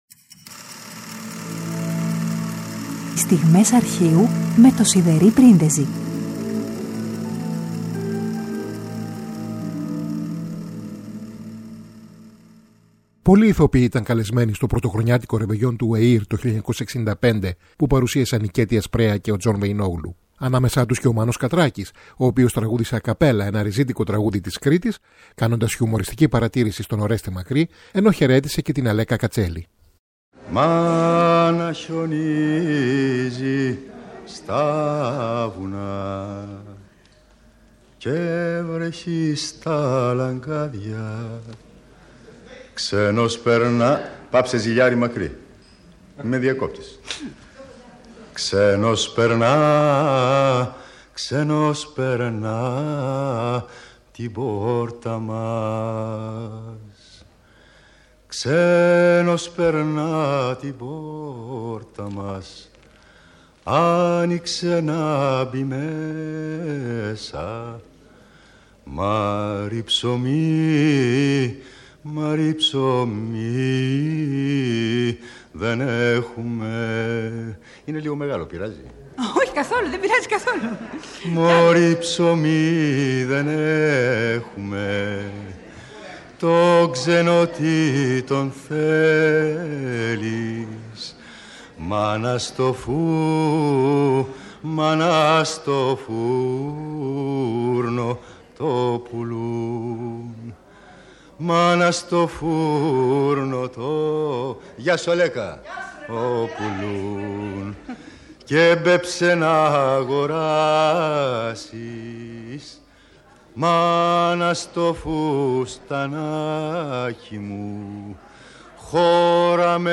Δευτέρα 31 Ιανουαρίου 2022: Ο Μάνος Κατράκης τραγουδά a capella ένα ριζίτικο τραγούδι της Κρήτης στο πρωτοχρονιάτικο ρεβεγιόν του Εθνικού Ιδρύματος Ραδιοφωνίας το 1965.